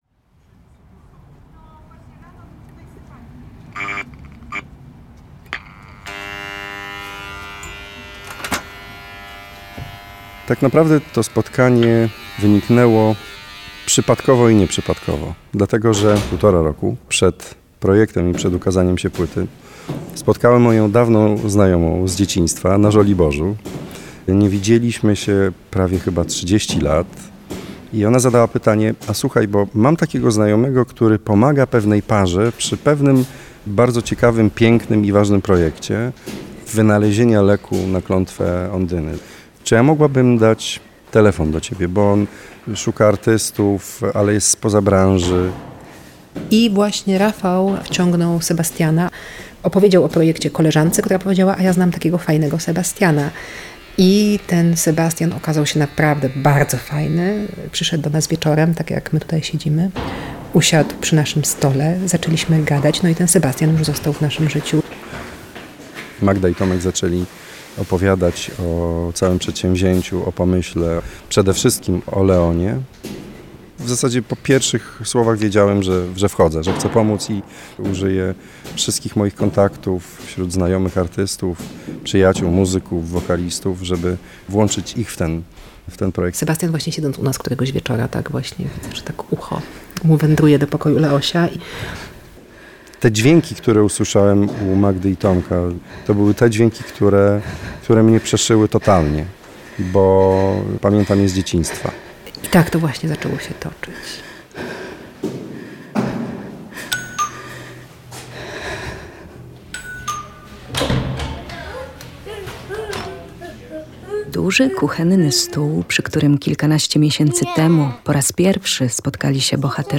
Nasza kołysanka Tagi: reportaż